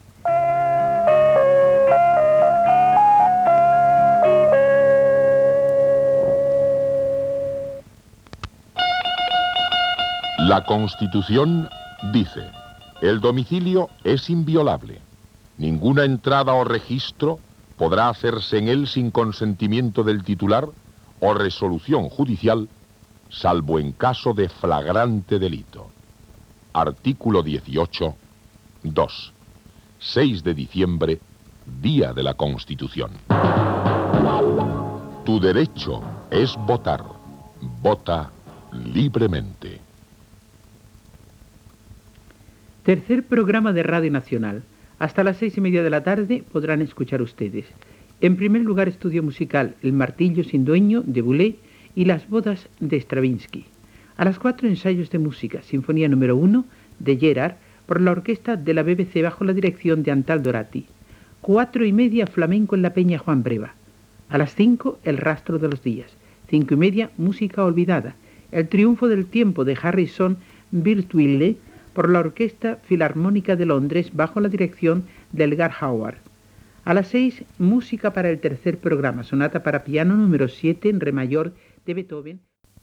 70ed4acebd18e468c16c3ff27944ddba3c2b7723.mp3 Títol Tercer Programa de RNE Emissora Tercer Programa de RNE Cadena RNE Titularitat Pública estatal Descripció Sintonia, article de la Constitució Espanyola, identificació i avanç de la programació.